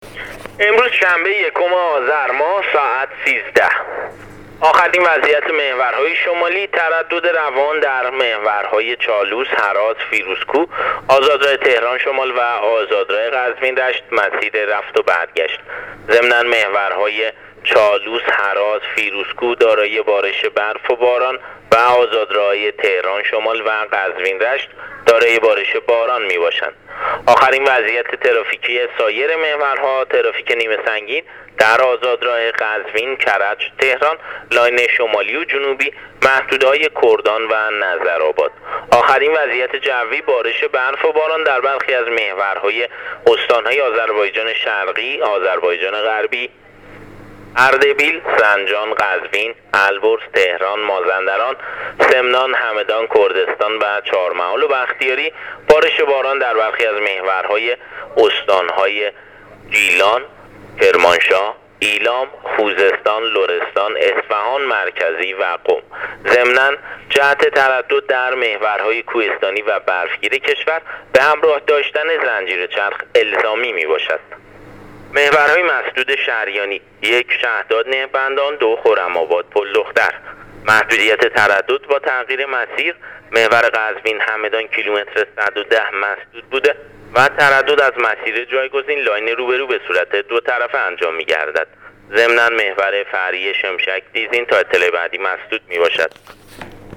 گزارش رادیو اینترنتی از وضعیت ترافیکی جاده‌ها تا ساعت ۱۳ اول آذر